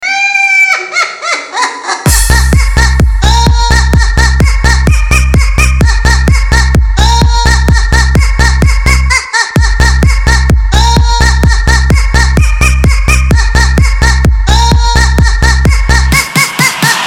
забавные
Melbourne Bounce
попугай
Миксованный смех попугая